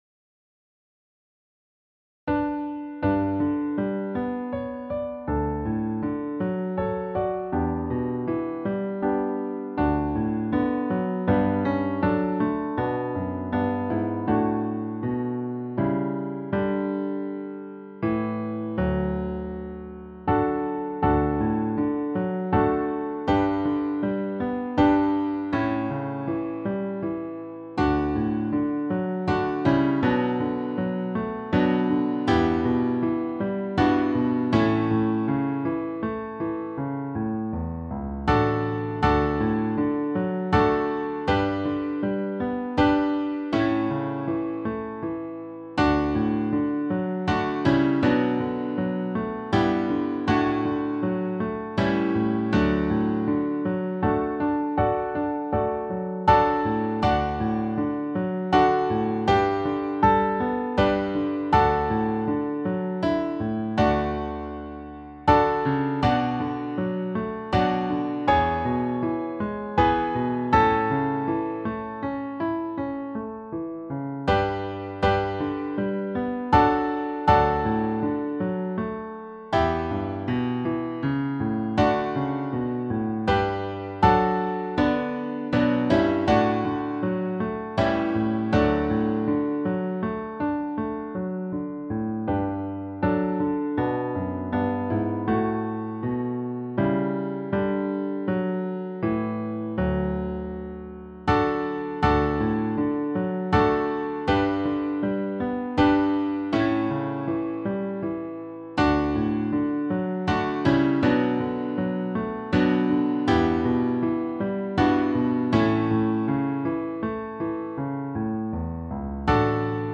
SATB+Piano